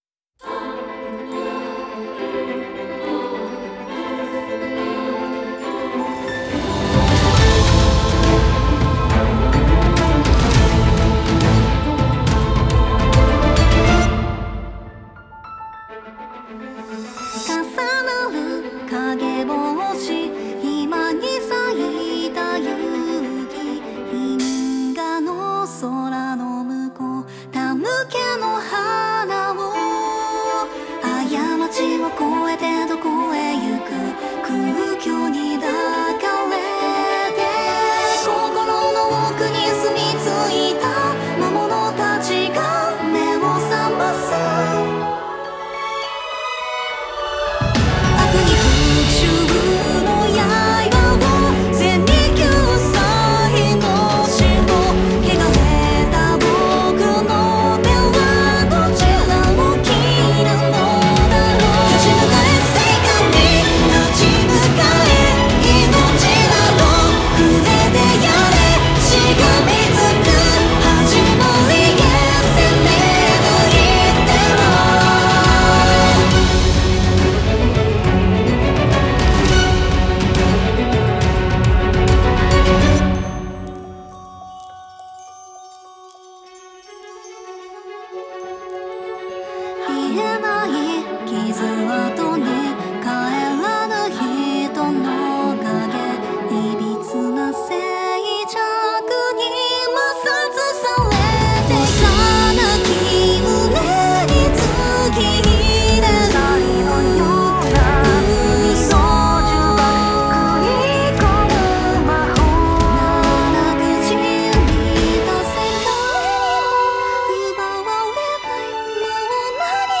Genre : Anime.